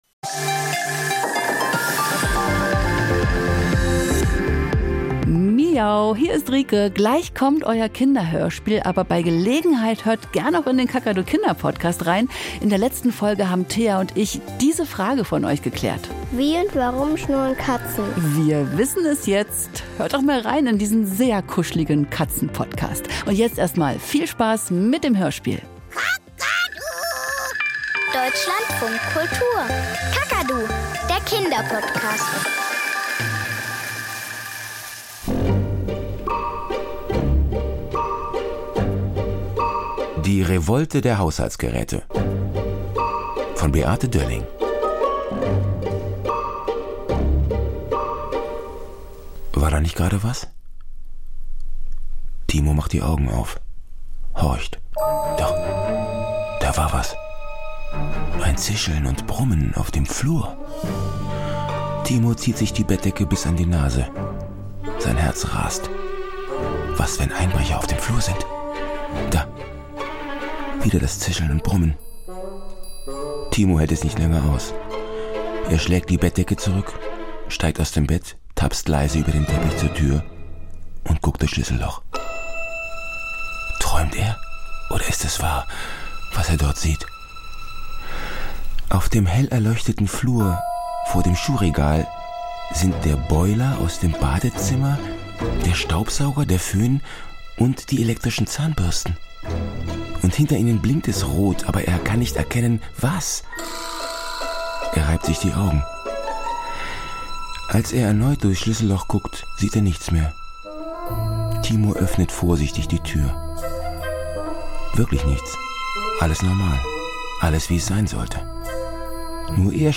Kinderhörspiel und Geschichten - Die Revolte der Haushaltsgeräte